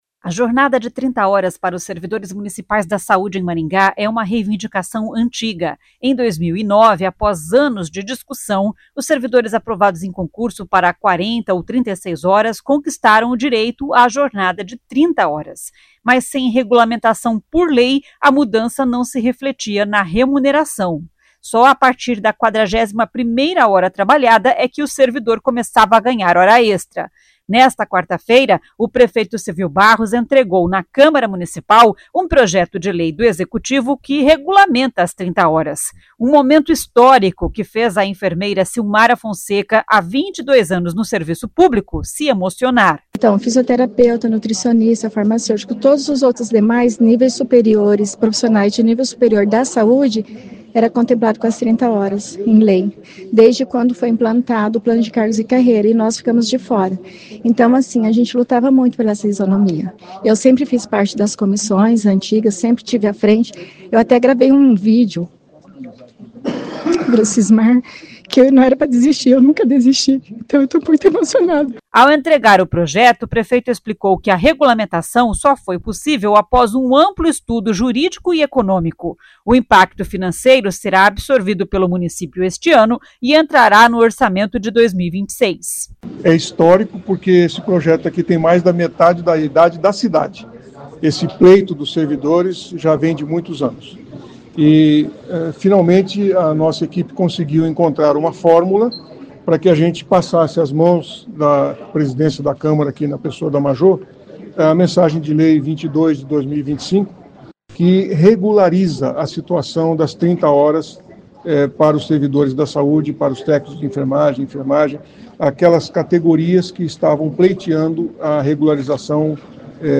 No rápido discurso, o prefeito disse que este fundamento caiu por terra porque muitos servidores têm outros empregos além do serviço público.